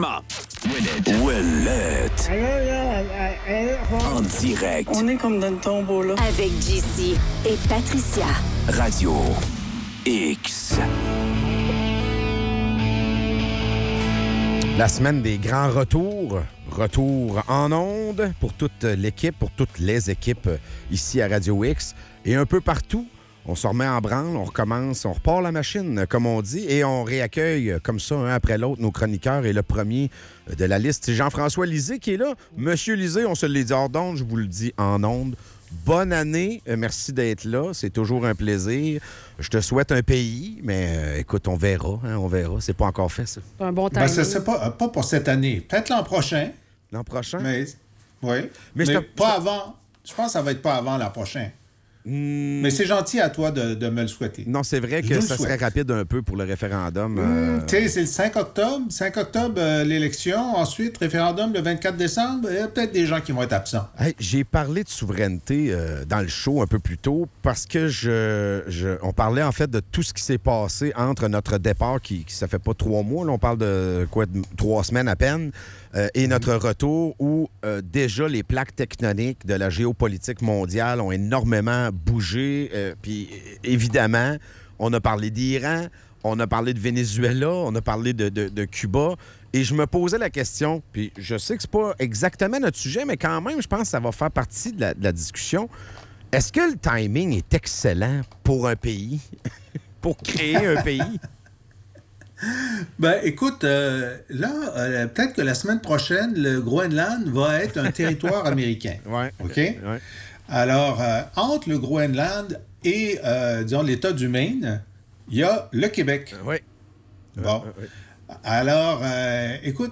En chronique, Jean-François Lisée.